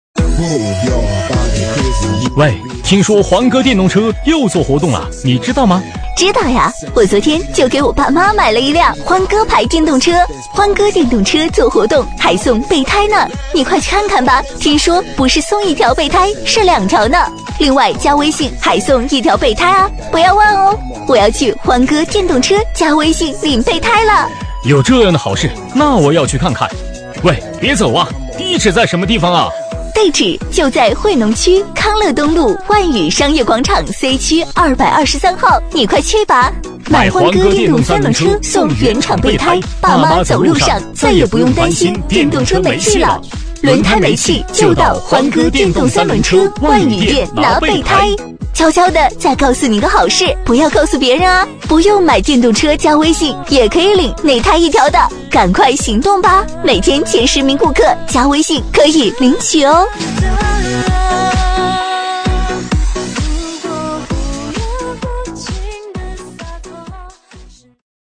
【男女对话】欢鸽电动车（男8+女36）
【男女对话】欢鸽电动车.mp3